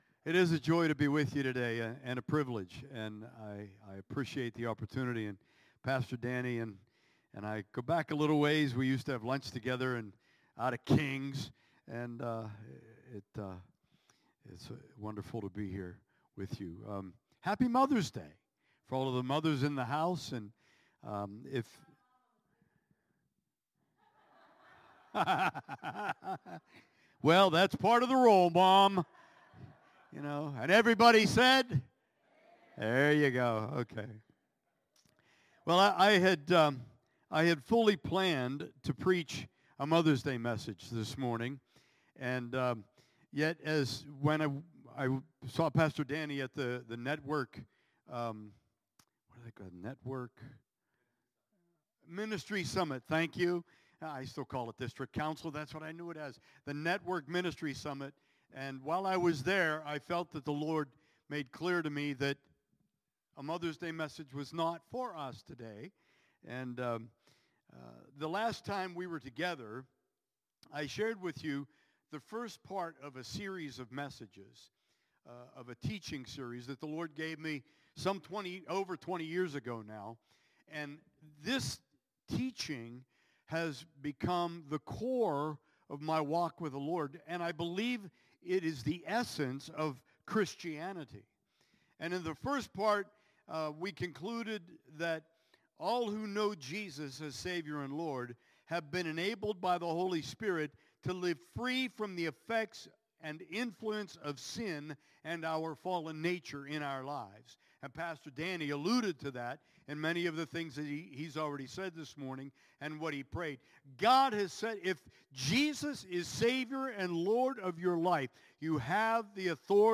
Sermons – Page 10 – Bethel Assembly of God